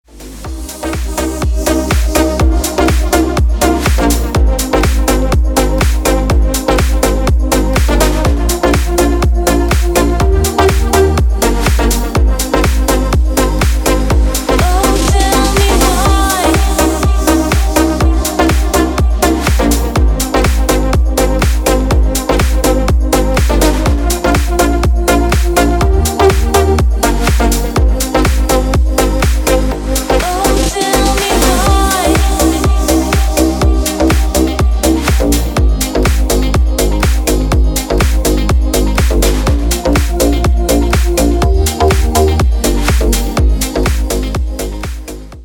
deep house
женский голос